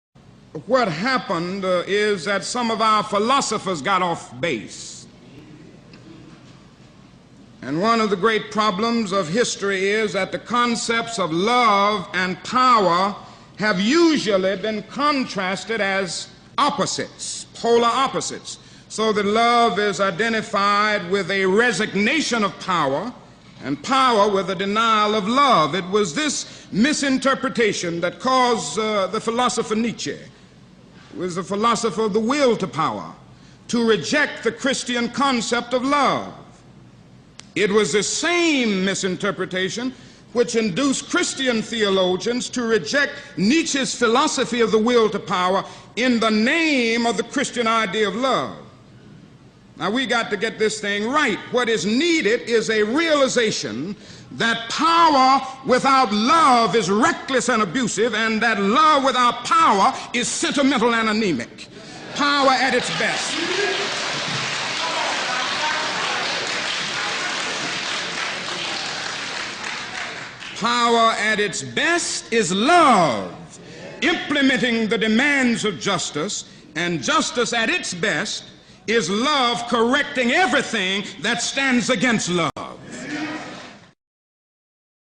by the way, here's an enlightening speech by martin luther king on his interpretation of the concept of love!